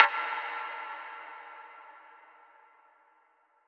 Metro Perc.wav